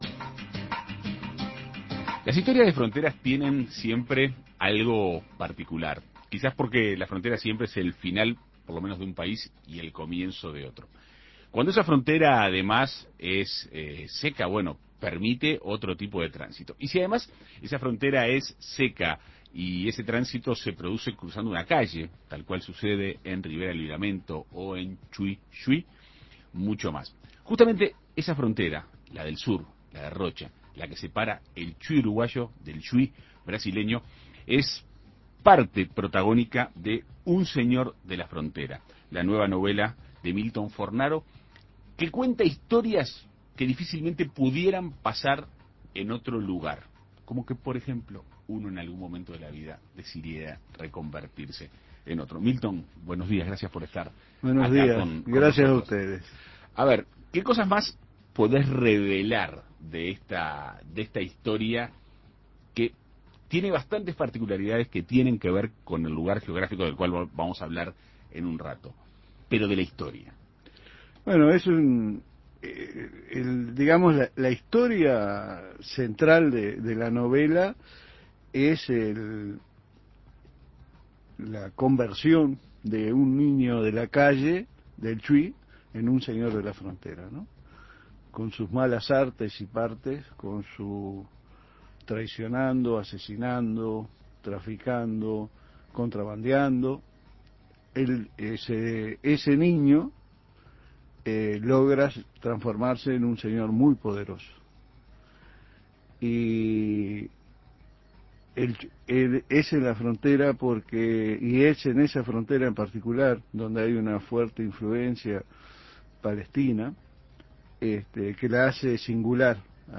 En Perspectiva Segunda Mañana dialogó con el autor.